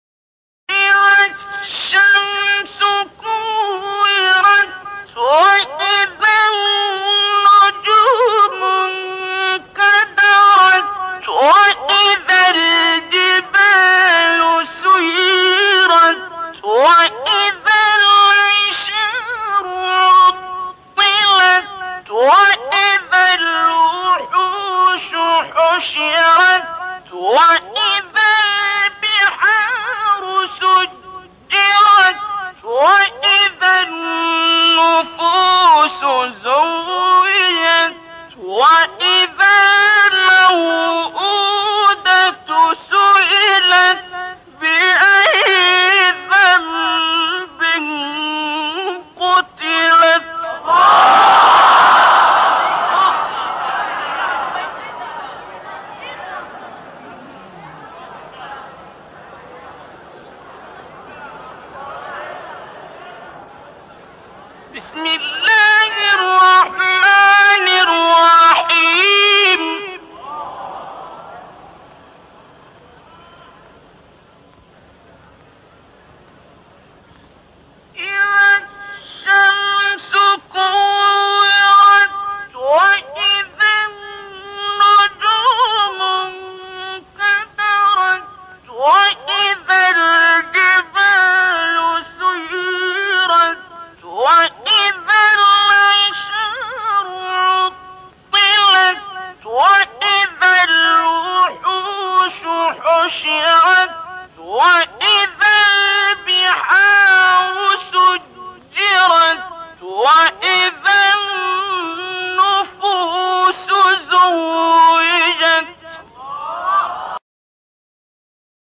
آیه 1-9 سوره تکویر استاد عبدالباسط عبدالصمد | نغمات قرآن | دانلود تلاوت قرآن